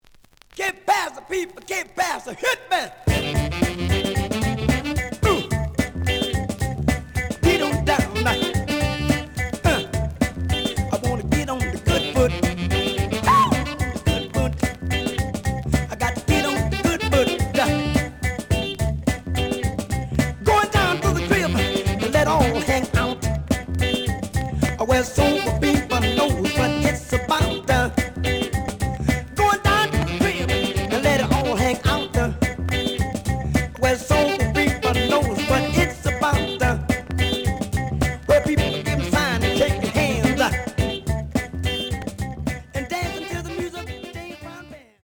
The audio sample is recorded from the actual item.
●Genre: Funk, 70's Funk
Some noise on both sides due to scratches.)